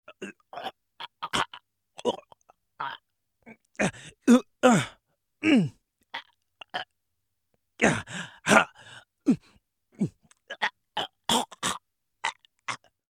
Звуки удушья
Голос человека при кислородном голодании